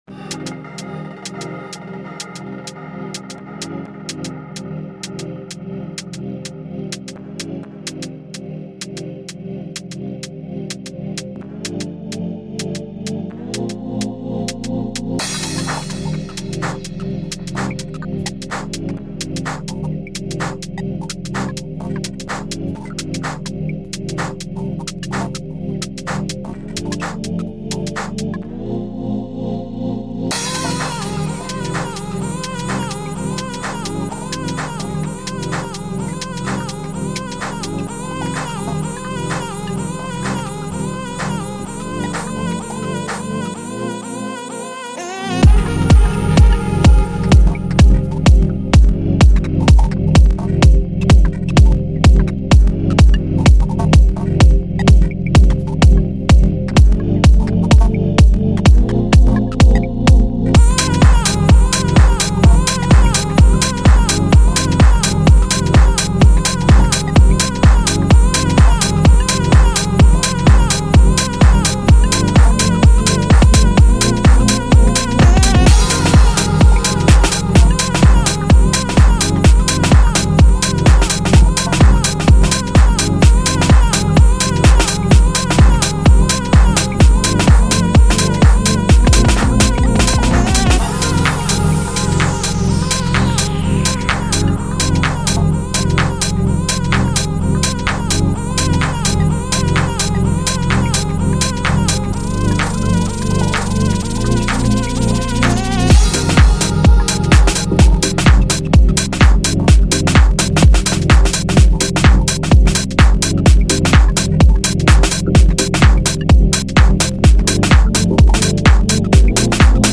I spent the day sitting on the couch yesterday with the MPC Live 2. “mixed” with the MPC speakers so do not judge :joy: